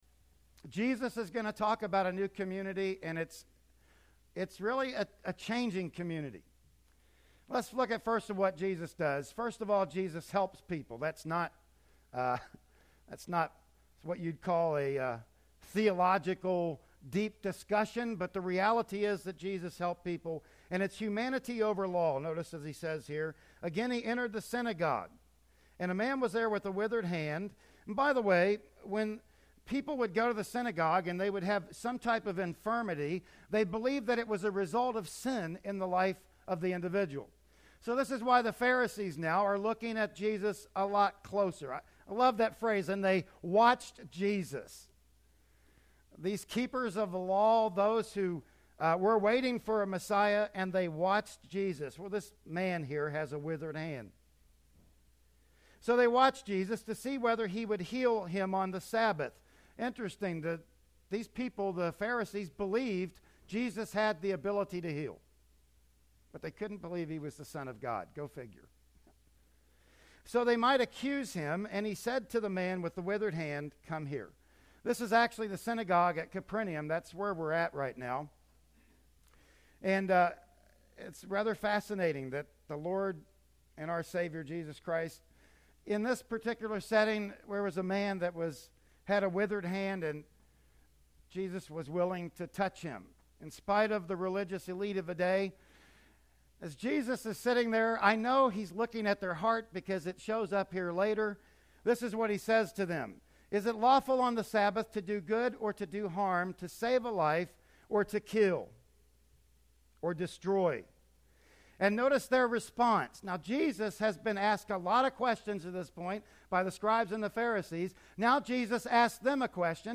"Mark 3:1-35" Service Type: Sunday Morning Worship Service Bible Text